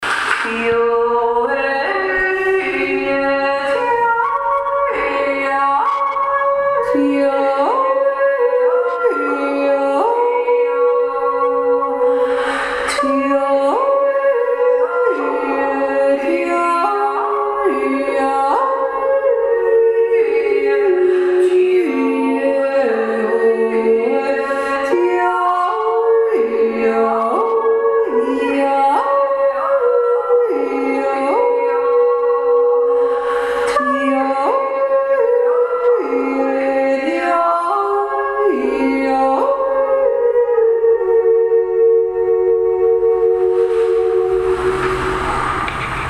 Zweite Stimme